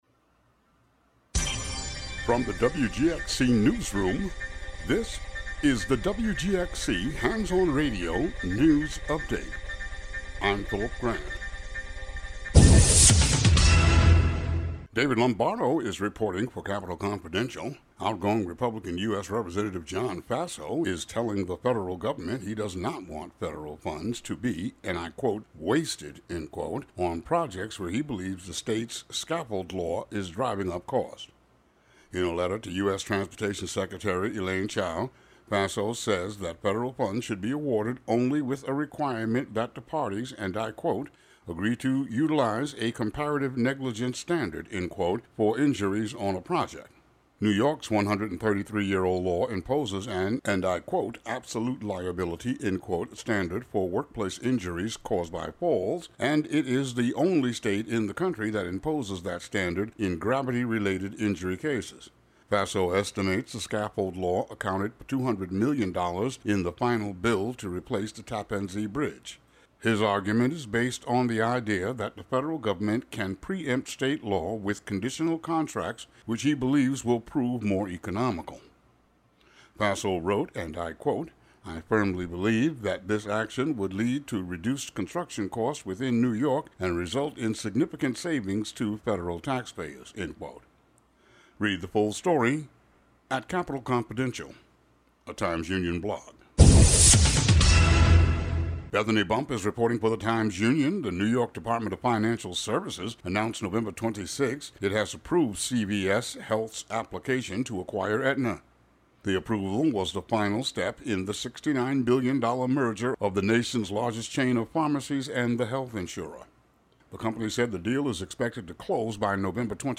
"All Together Now!" is a daily news show brought t...